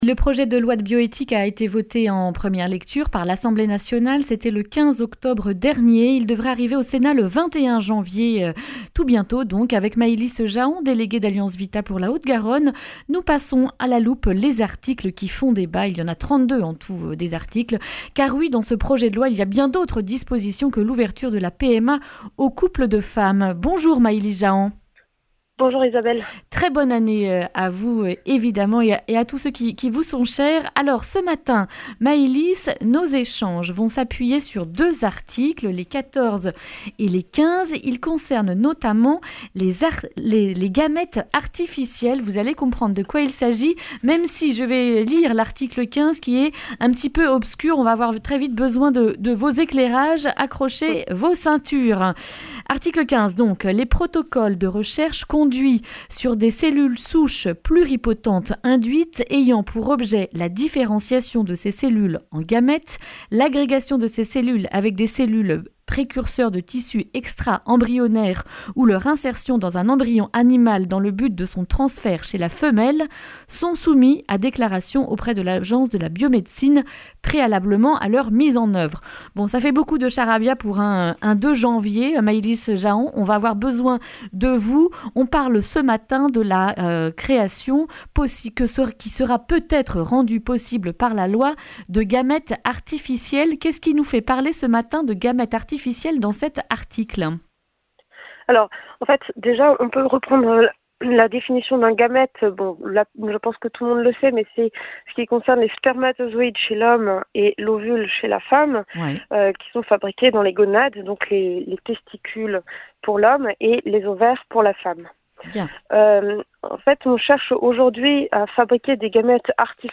jeudi 2 janvier 2020 Le grand entretien Durée 10 min